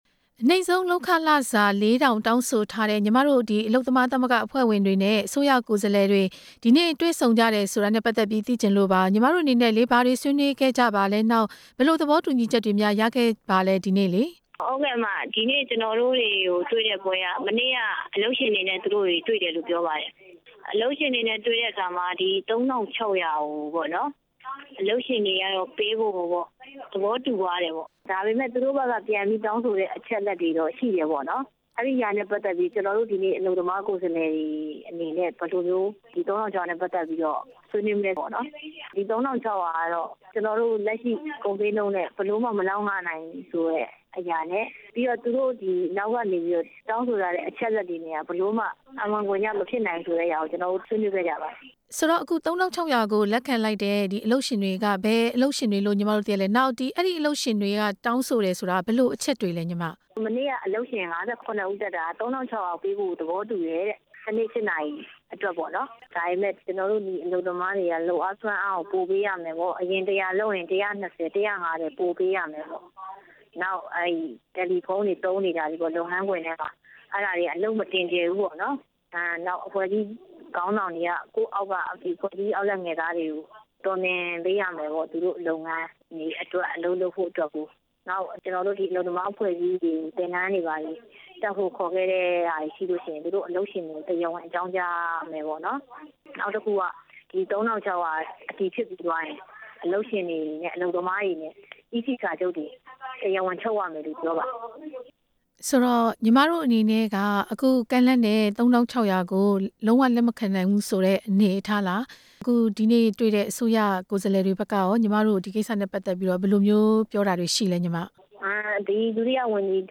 ဒီနေ့ လှိုင်သာယာမြို့နယ် ကနောင်ခန်းမမှာ တစ်ရက် လုပ်ခ ကျပ် ၄၀၀၀ သတ်မှတ်ပေးဖို့ တောင်းဆိုထားတဲ့ အလုပ် သမားကိုယ်စားလှယ်တွေကို ခေါ်ယူတွေ့ ဆုံတဲ့ အခမ်းအနားမှာ အလုပ်သမား၊ အလုပ်အကိုင်နဲ့ လူမှုဖူလုံရေးဝန်ကြီး ဌာန ဒုတိယဝန်ကြီး ဒေါ်ဝင်းမော်ထွန်းက ပြောပါတယ်။